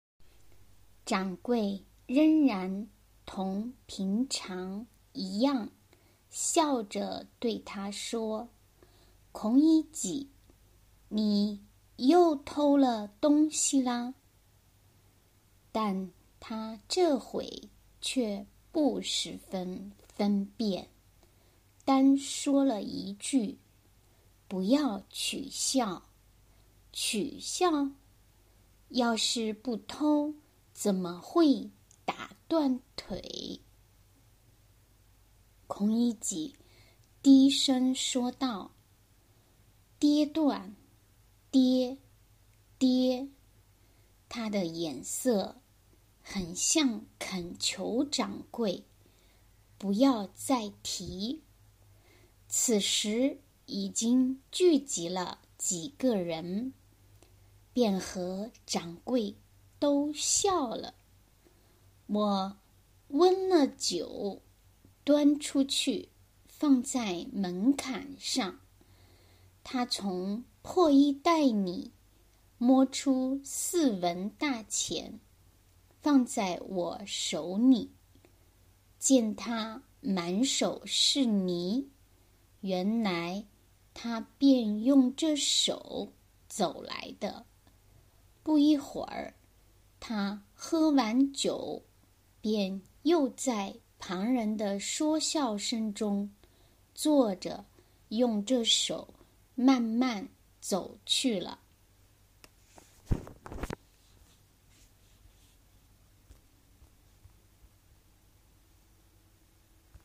では、最初に第12回目のピンイン、朗読と翻訳例です。
発音